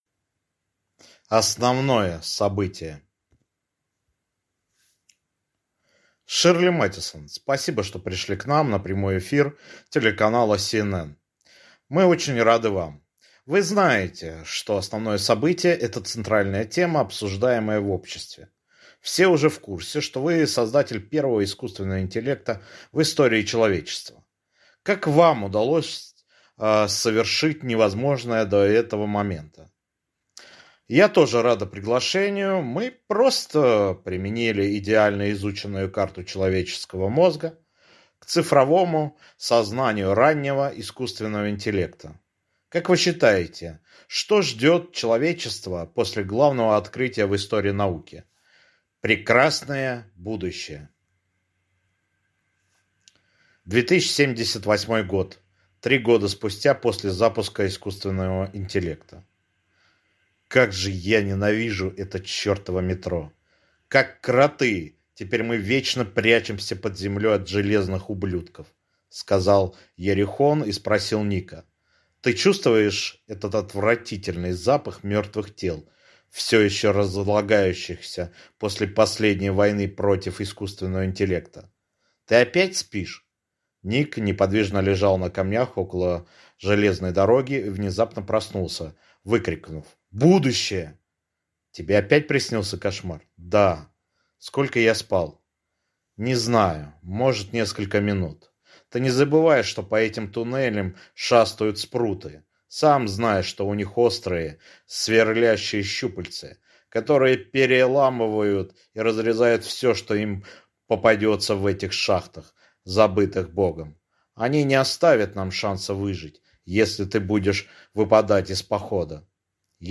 Аудиокнига Основное событие | Библиотека аудиокниг